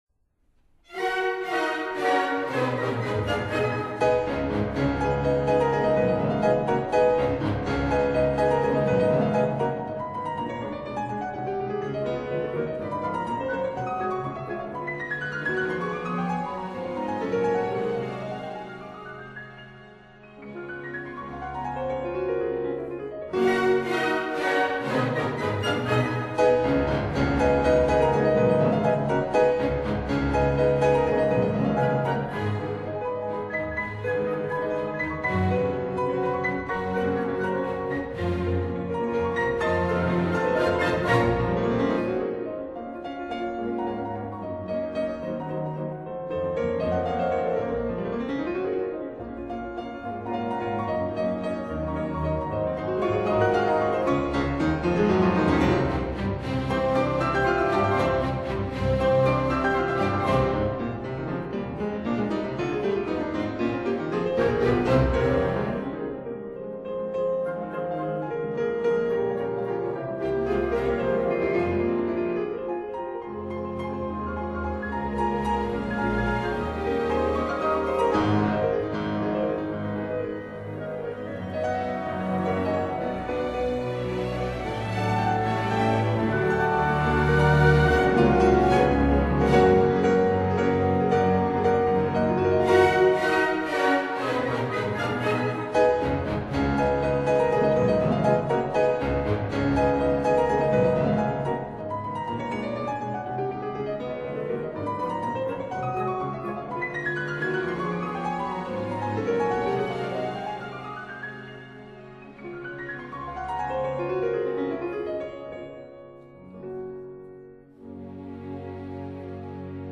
所屬時期／樂派        浪漫主義